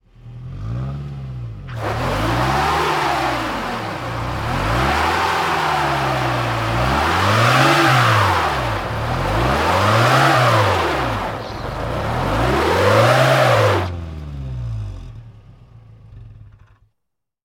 Car Tires Snow; Auto Stuck In Snow Tire Spins, Close Perspective 3x